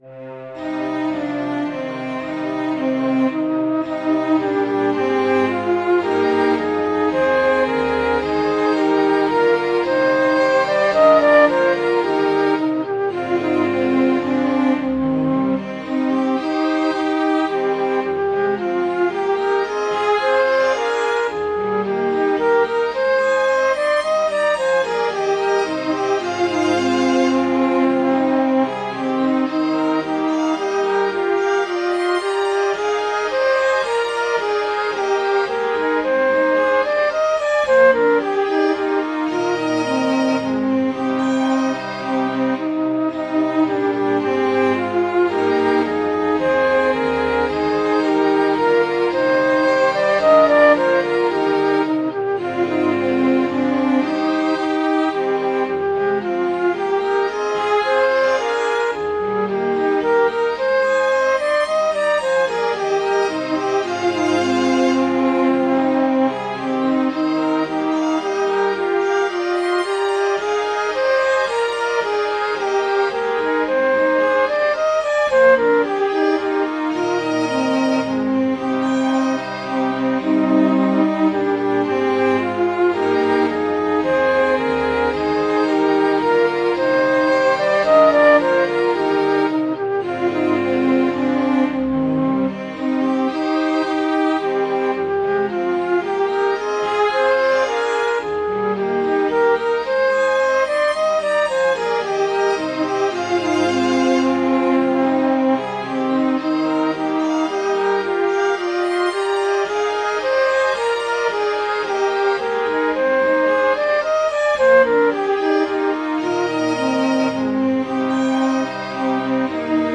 Midi File